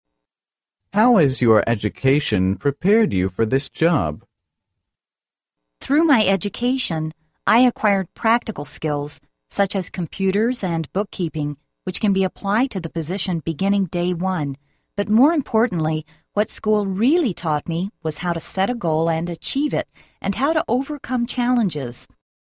真人朗读，帮助面试者迅速有效优化面试英语所需知识，提高口语能力。